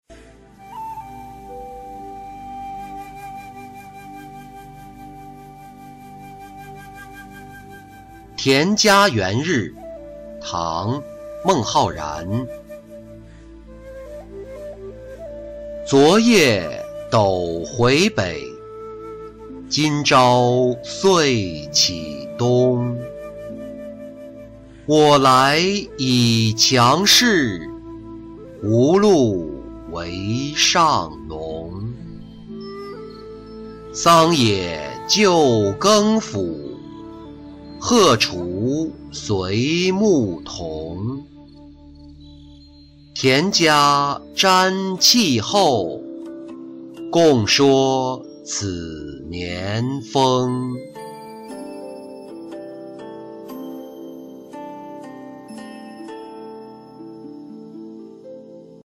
田家元日-音频朗读